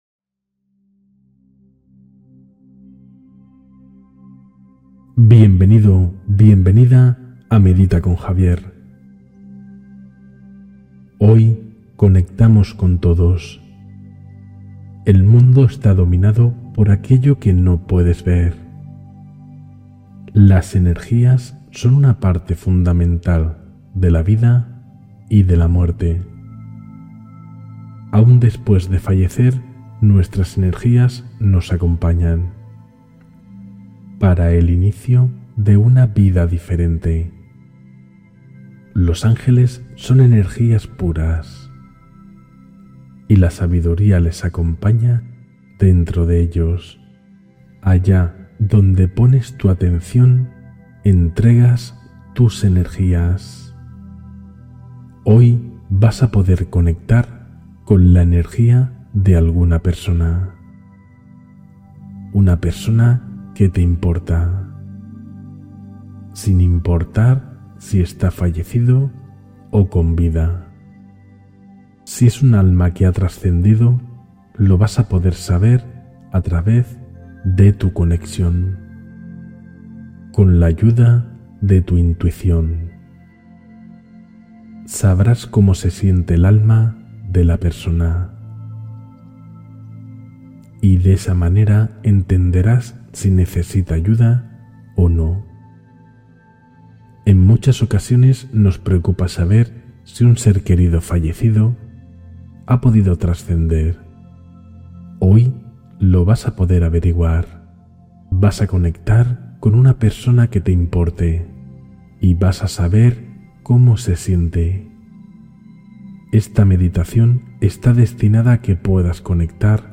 Activación de la Intuición a Distancia: Meditación de Presencia Energética